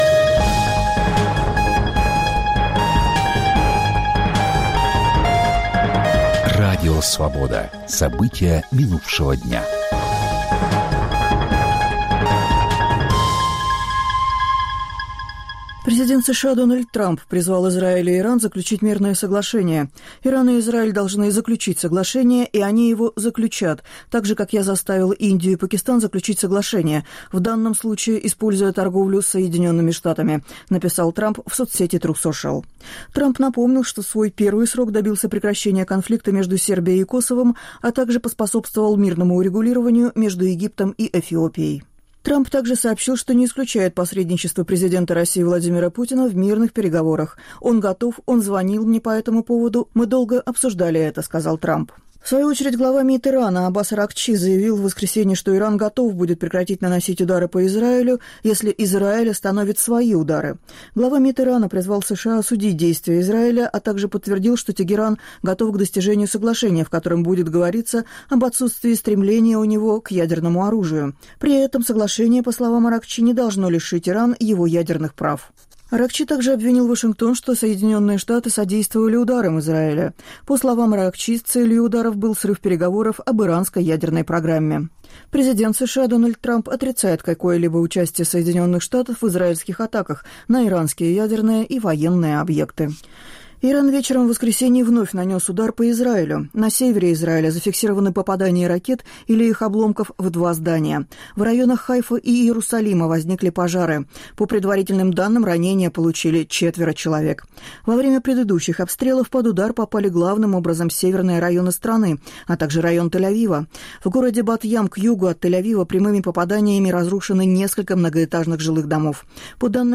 Аудионовости